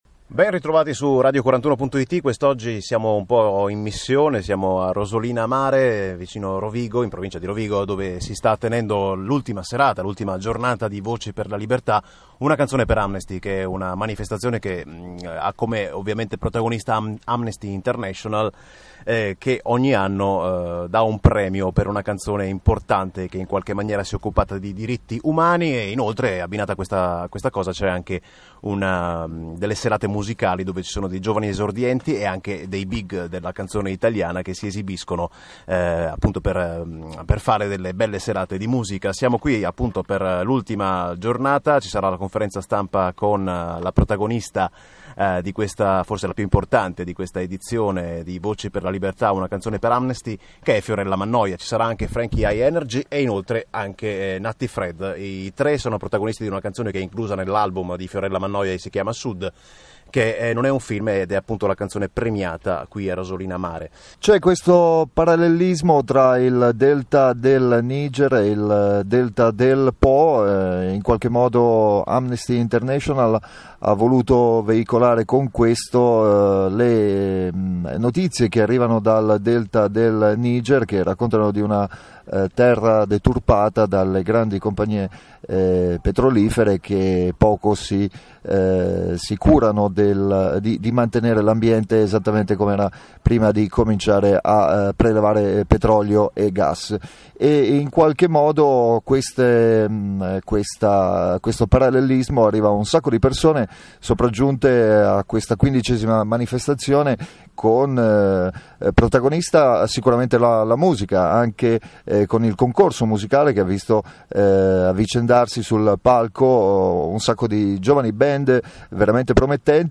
Appuntamento per oggi alle 19 con il nostro Speciale realizzato a Rosolina, durante la giornata conclusiva della manifestazione.